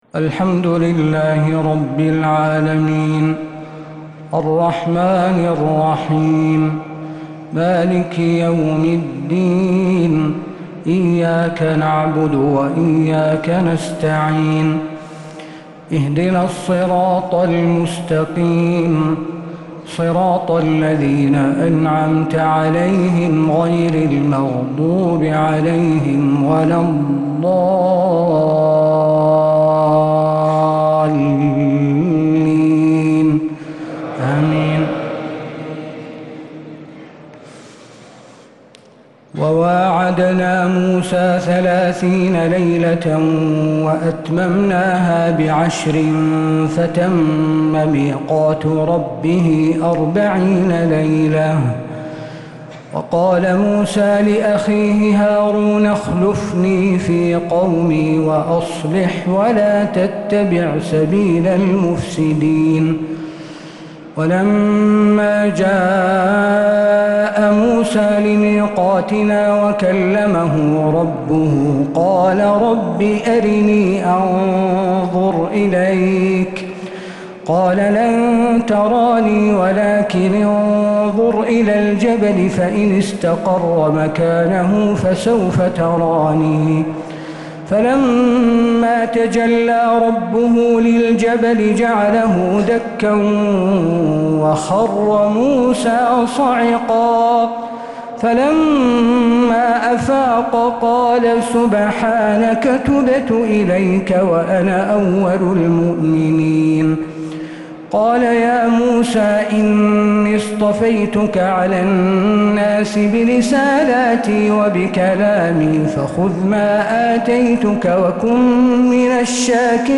تراويح ليلة 12 رمضان 1446هـ من سورة الأعراف (142-200) | taraweeh 12th niqht Surat Al-Araf 1446H > تراويح الحرم النبوي عام 1446 🕌 > التراويح - تلاوات الحرمين